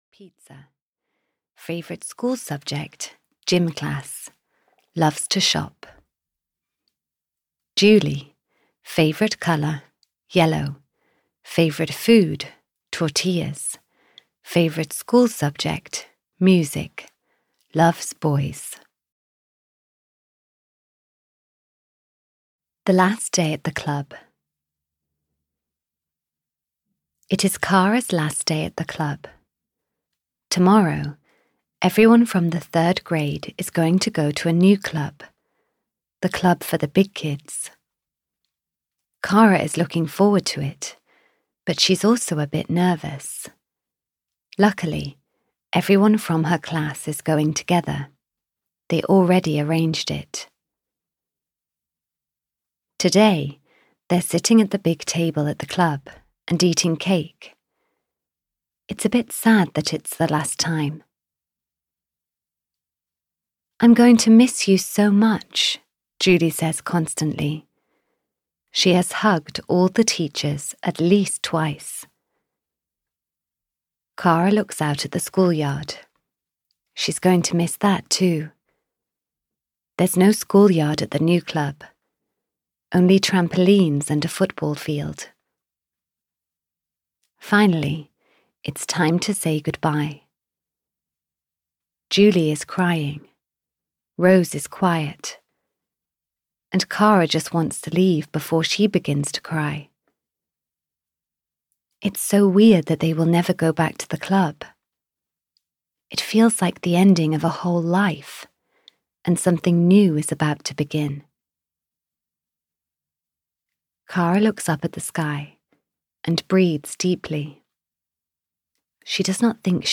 K for Kara 8 - The New Club (EN) audiokniha
Ukázka z knihy